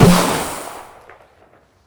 FlareGun_Shoot 01.wav